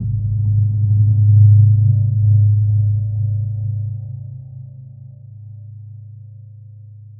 Index of /musicradar/impact-samples/Low End
Low End 05.wav